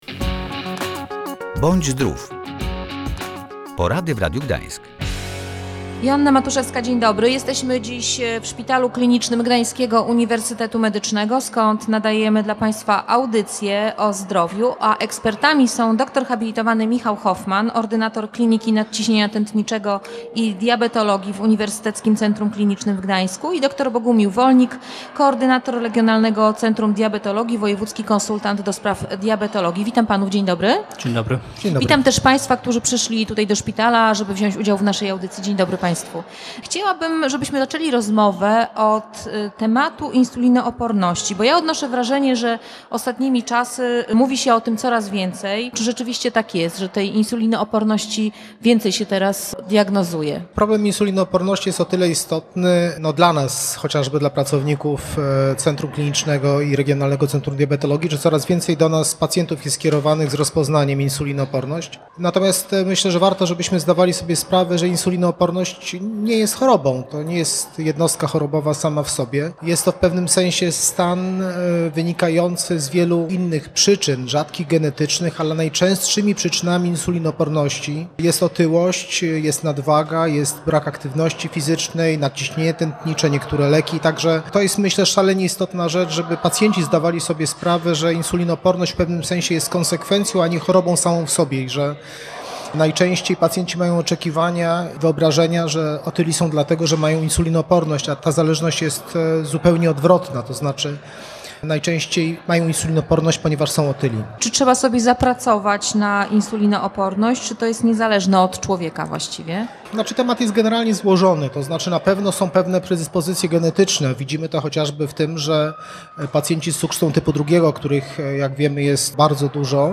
Insulinooporność nie jest chorobą, to konsekwencja innych schorzeń, albo niezdrowego trybu życia - mówili w czasie spotkania ze słuchaczami Radia